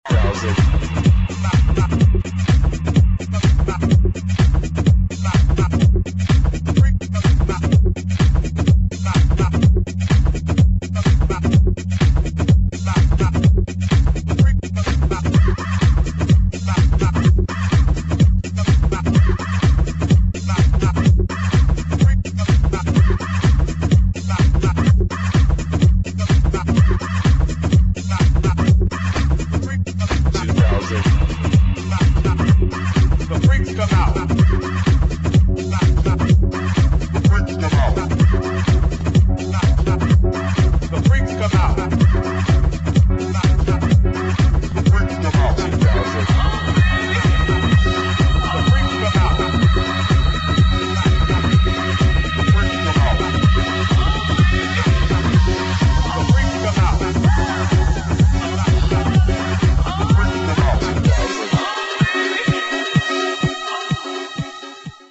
[ HOUSE | TECH HOUSE ]